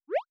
SFX_Dialog_Open_02.wav